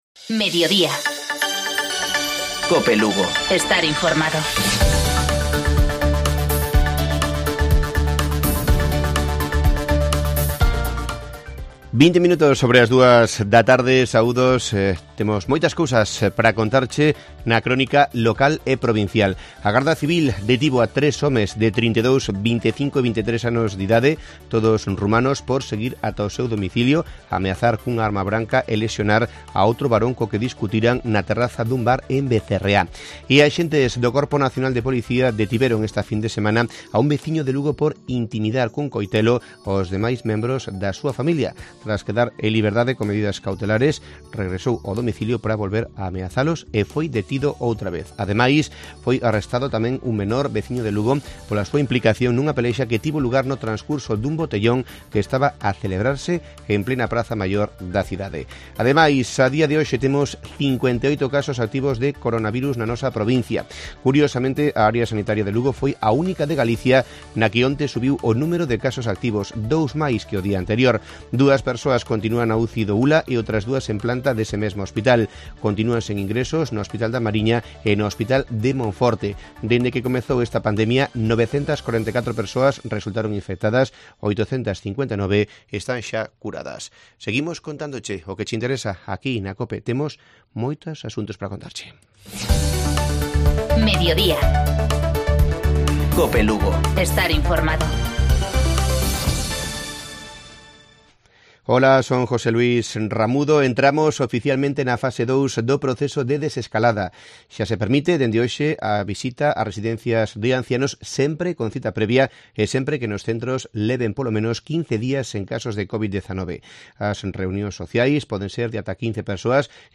Informativo Mediodía Cope. Lunes, 25 de mayo. 14:20-14-30 horas.